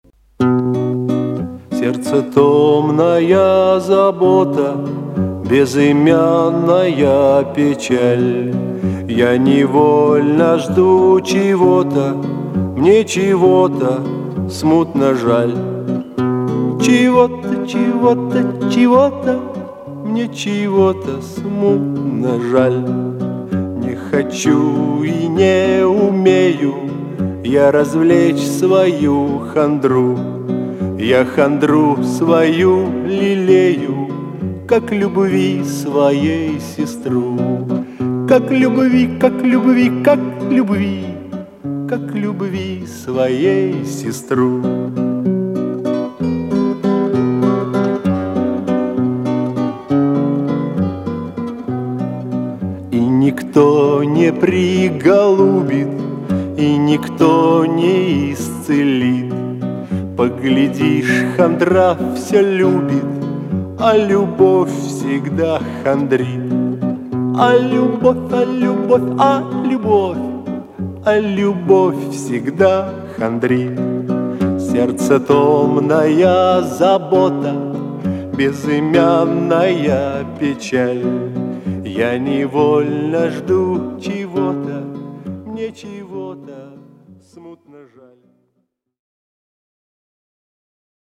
саундтрек из фильма